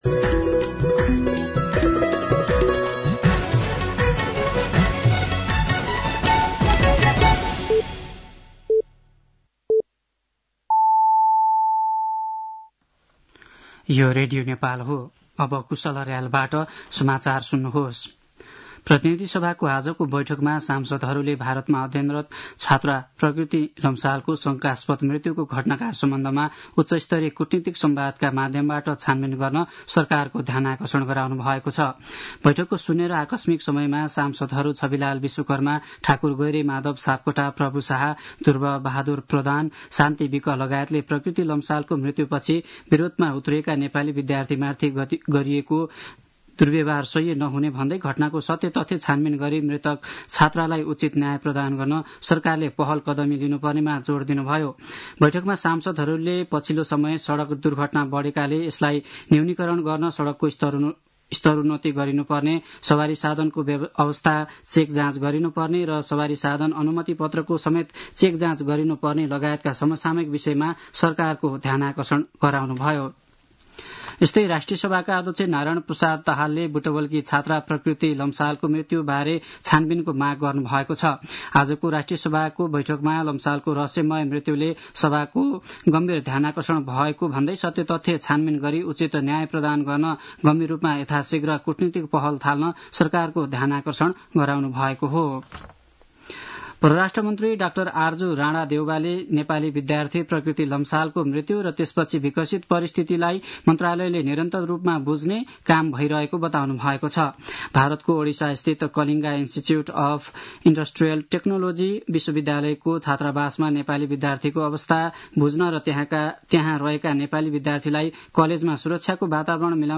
साँझ ५ बजेको नेपाली समाचार : ७ फागुन , २०८१